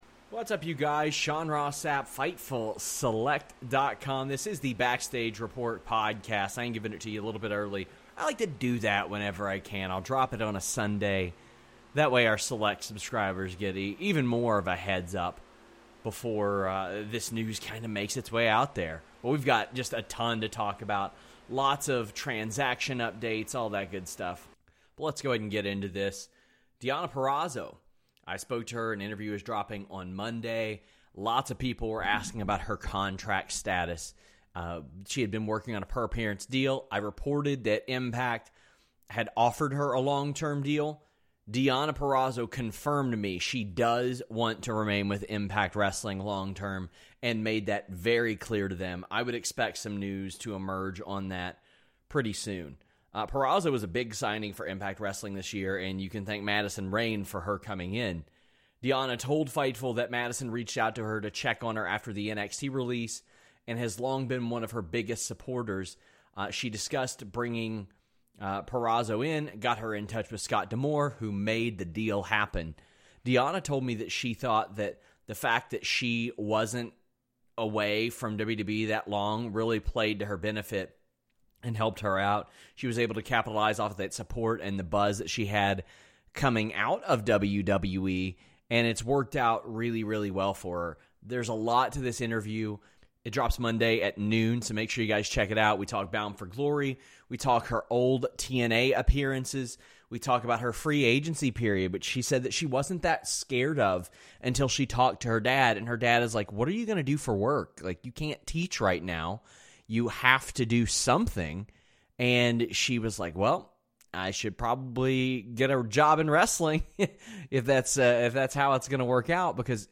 Is it just me or was the volume down really low?